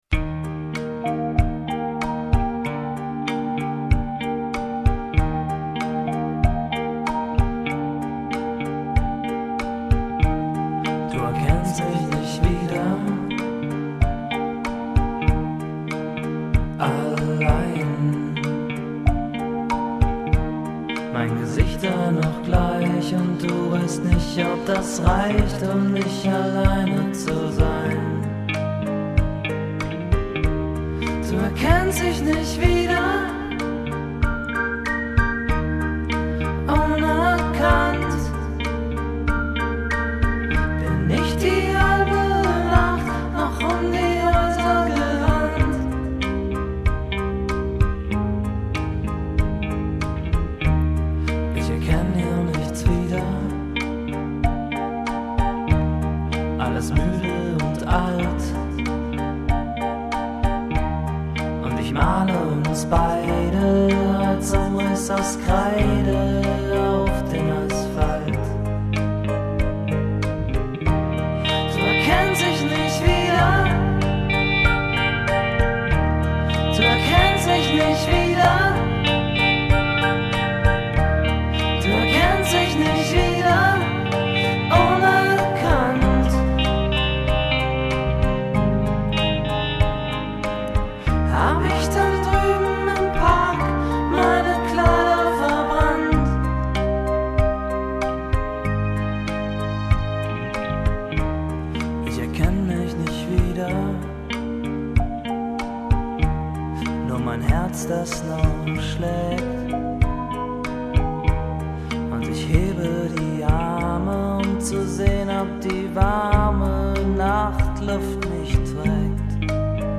Cover-Versionen